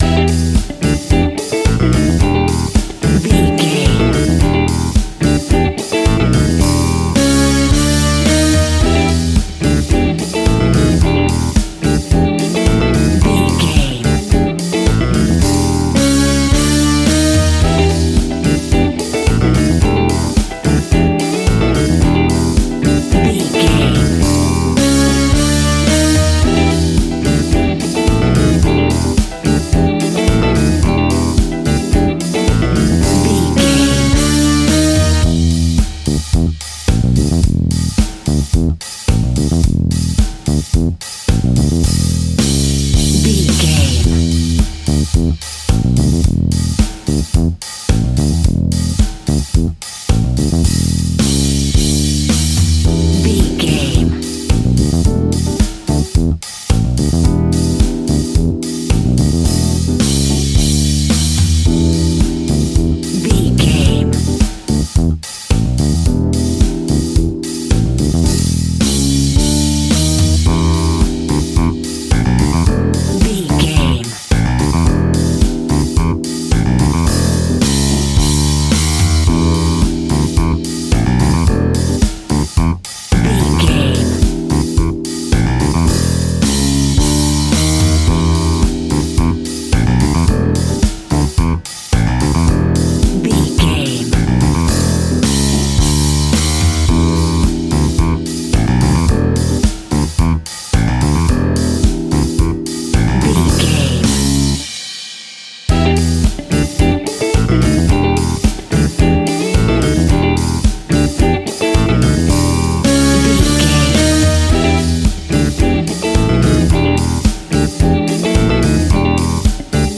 House and Disco.
groovy
hypnotic
uplifting
electric guitar
bass guitar
drums
electric piano
funky house
deep house
upbeat
driving
energetic
electronic drums
synth lead
synth bass